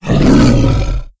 c_sibtiger_atk3.wav